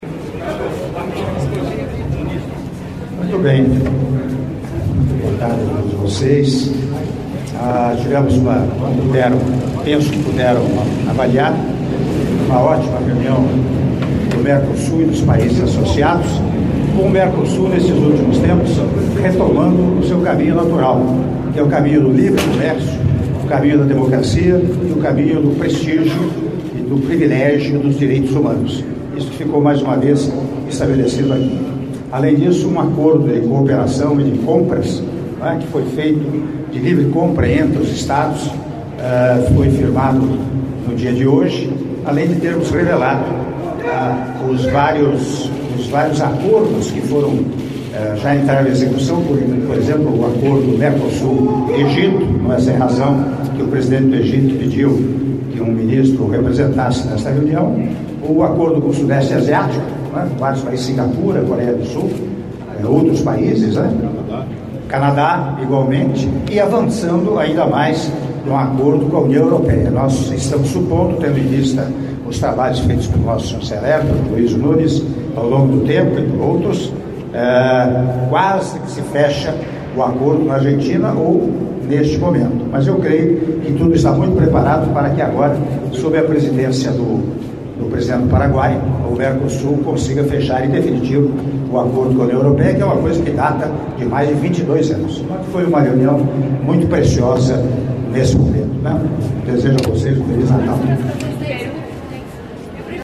Áudio da Entrevista coletiva concedida pelo Presidente da República, Michel Temer, após LI Cúpula de Chefes de Estado do Mercosul e Estados Associados - Palácio Itamaraty (01min43s)